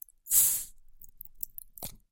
Звуки стеклянной бутылки
Шум открывающейся бутылки пива